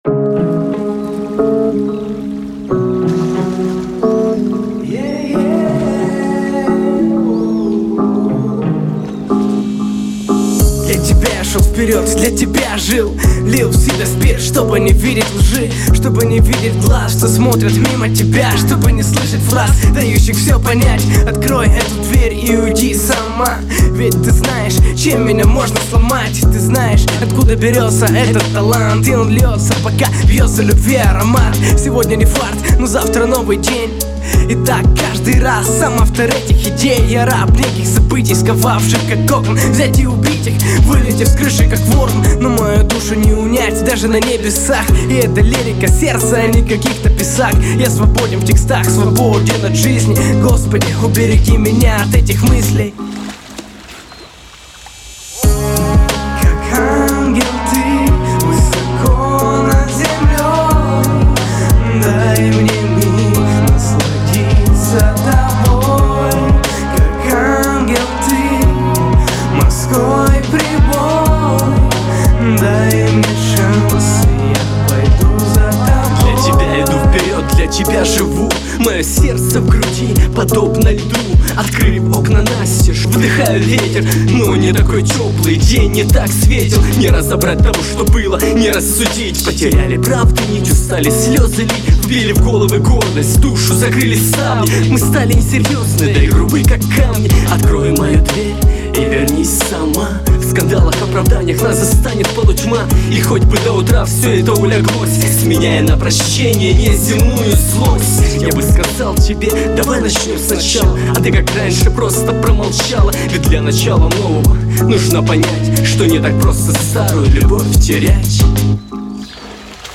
Marshall Electronics MXL USB.006